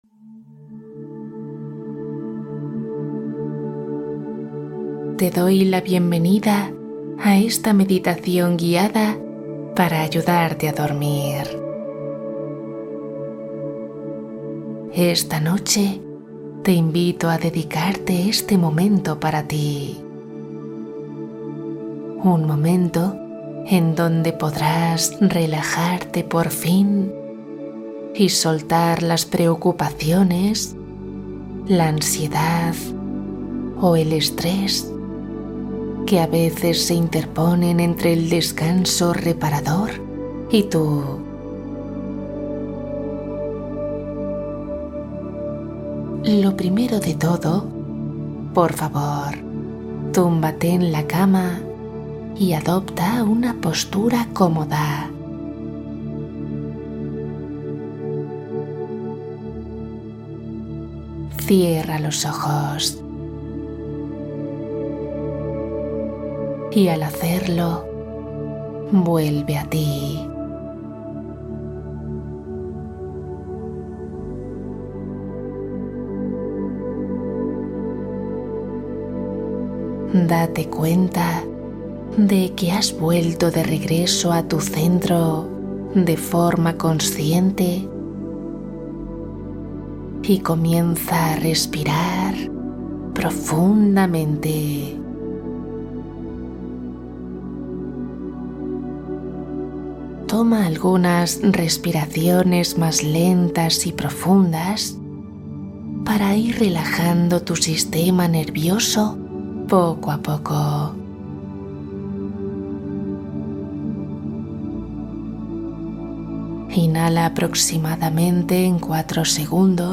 Cuento para dormir profundamente Relajación con voz suave